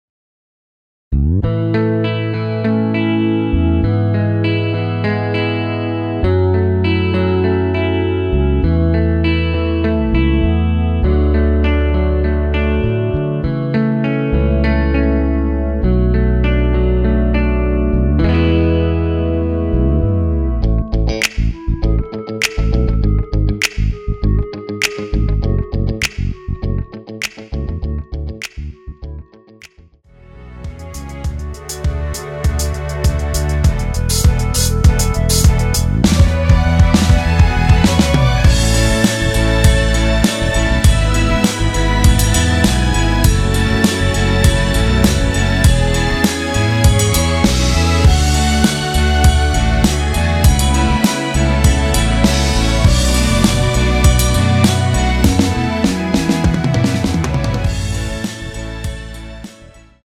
원키 멜로디 포함된 MR입니다.(미리듣기 확인)
멜로디 MR이란
앞부분30초, 뒷부분30초씩 편집해서 올려 드리고 있습니다.